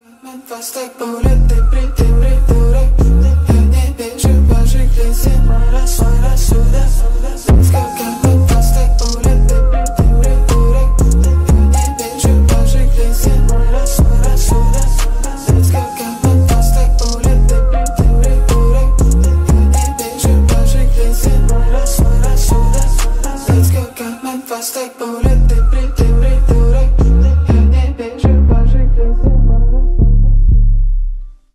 Рэп и Хип Хоп
тихие